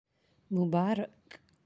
(mubaarak)
mubaraak.aac